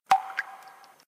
Lock.ogg